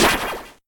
ballStart.ogg